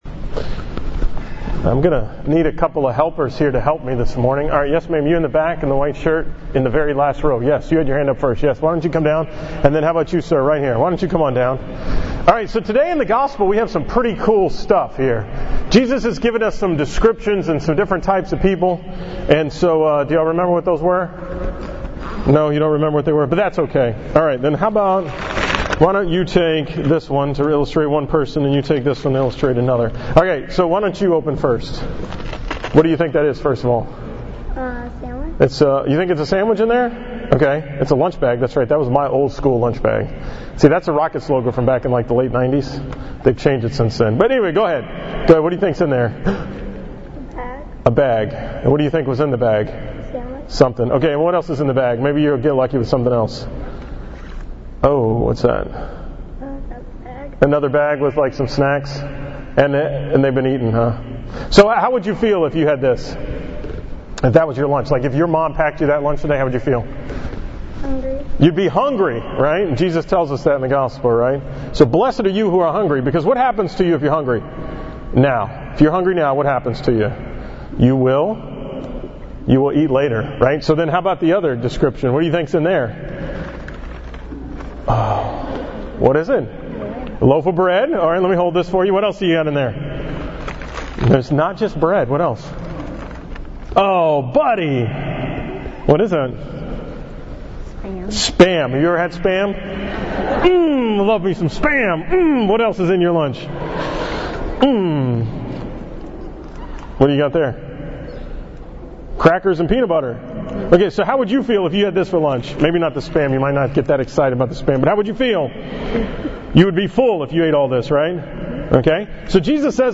From the school Mass at Christ the Redeemer school on September 7, 2016